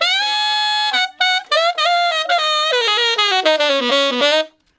068 Ten Sax Straight (Ab) 10.wav